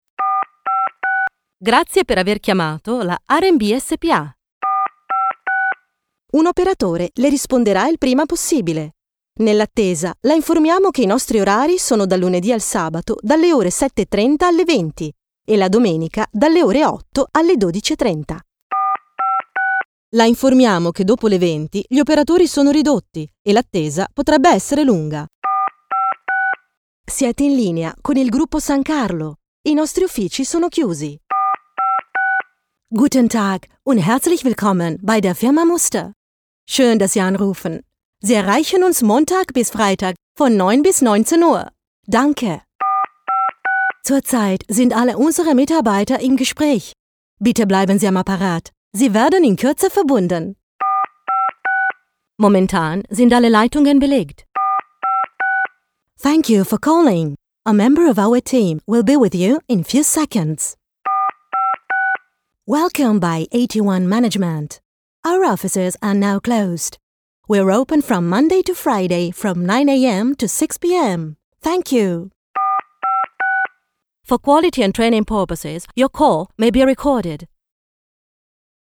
Professional singer and Italian voice talent whose expressive and versatile voice can range from smooth and sexy to friendly and upbeat, from warm and compassionate to authoritative and professional and is ideal for radio and TV commercials, telephone on hold, e-learning and corporates.
Sprechprobe: Sonstiges (Muttersprache):
ON HOLD (it, deu, eng).mp3